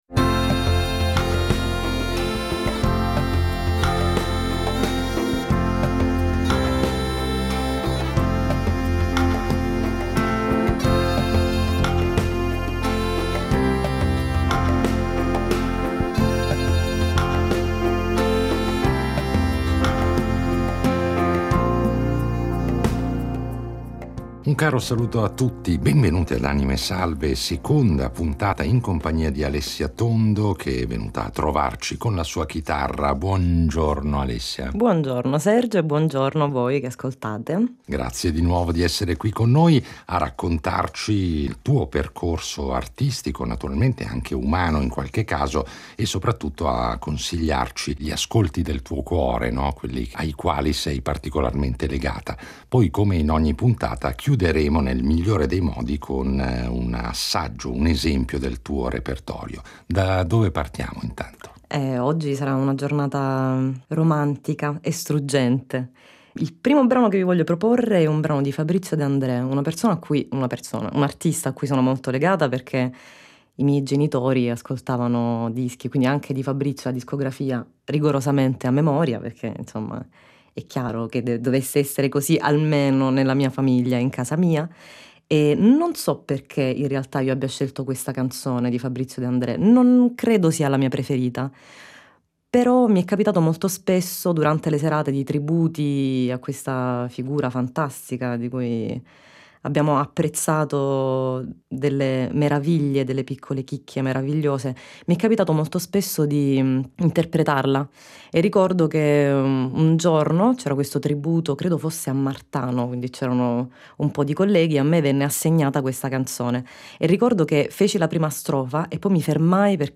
È ormai imminente l’uscita del suo primo album da solista, del quale ascolteremo senz’altro alcuni esempi con esecuzioni esclusive, realizzate solo per noi.